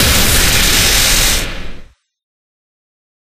Thunder14.ogg